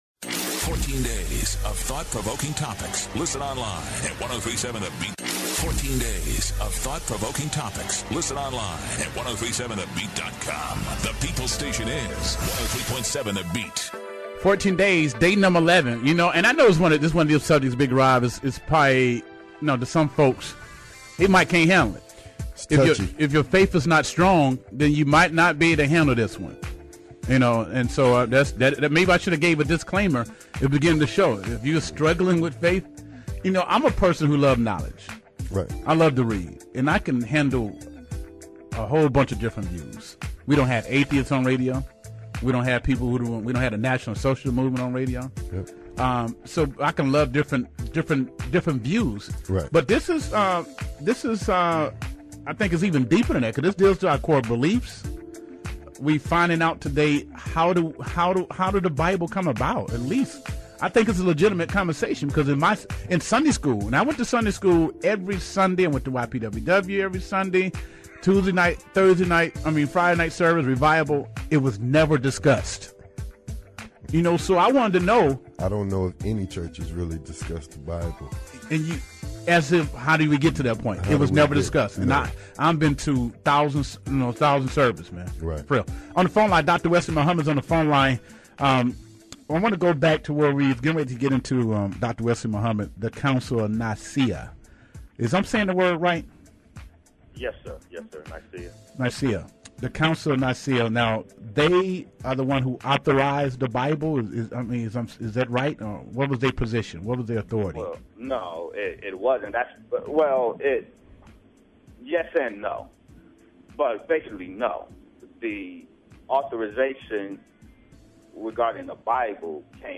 In depth interview